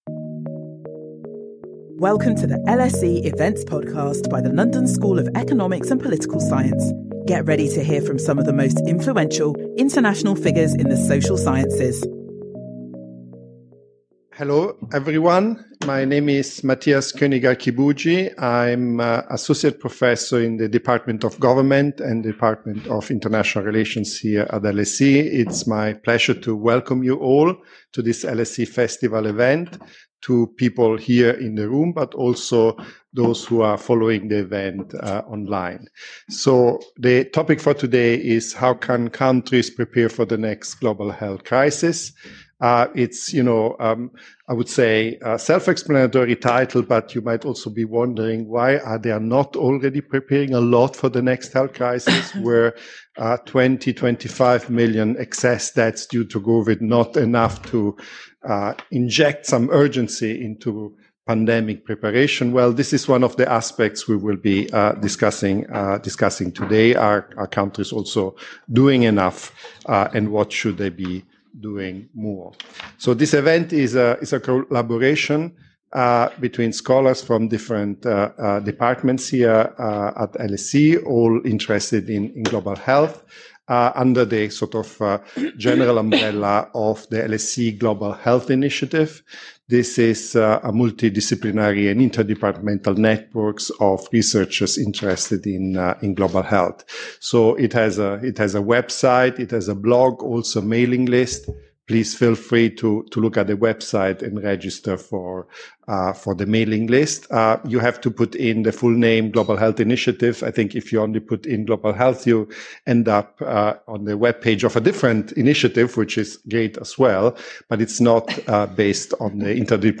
Our panel assess countries’ prevention, preparedness and response for the next global health crisis after the COVID-19 pandemic.